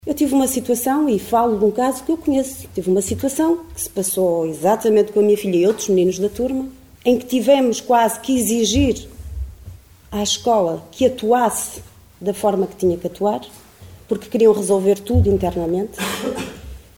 Liliana Silva, da Coligação O Concelho em Primeiro (OCP), acusou na última reunião do executivo a direção do Agrupamento de Escolas de Caminha de abafar os casos de bullying (e outros).